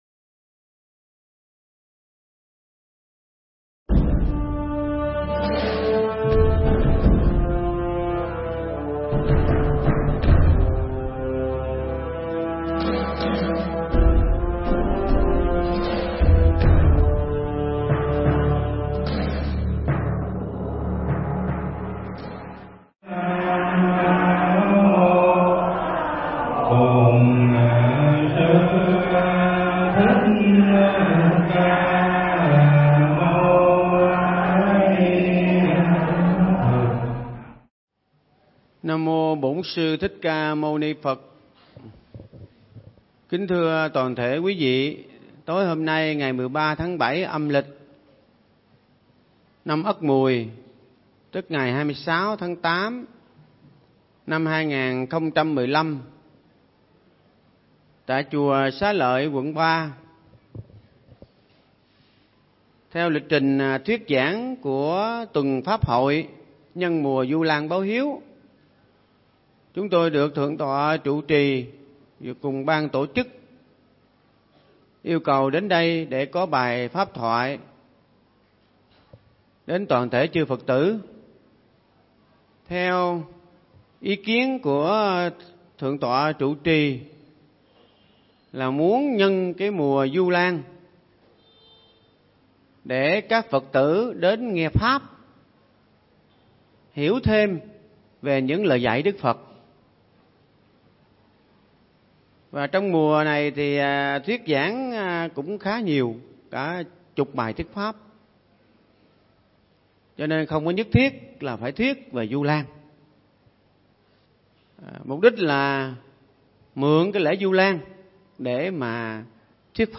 Nghe Mp3 thuyết pháp Mặc Kệ Nó